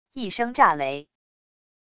一声炸雷wav音频